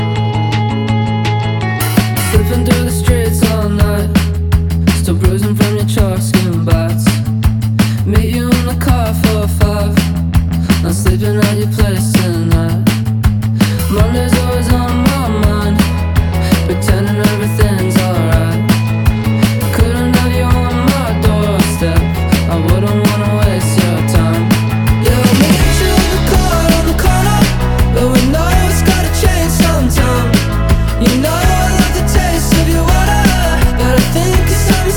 Нестандартные аккорды и неожиданные переходы
2025-06-27 Жанр: Альтернатива Длительность